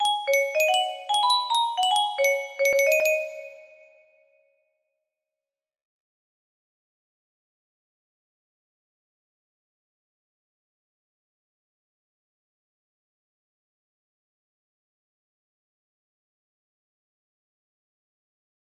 Rand music box melody